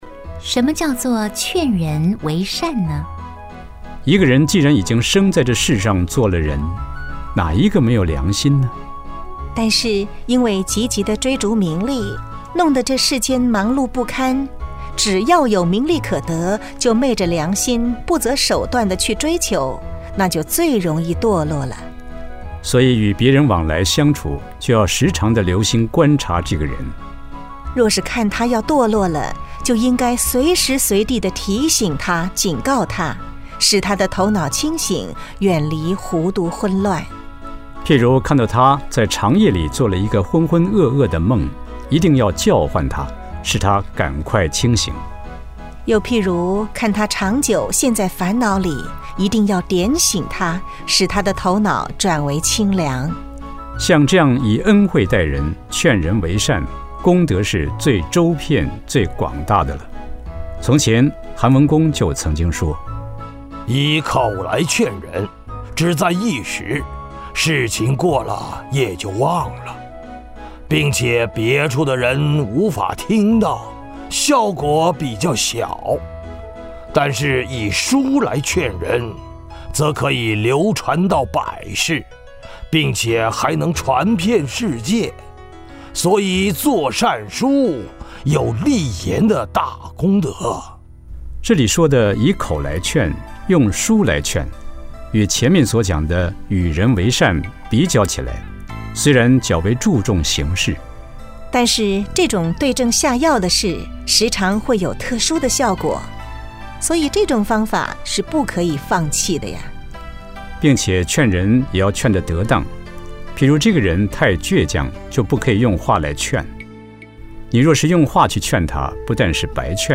了凡四訓有聲書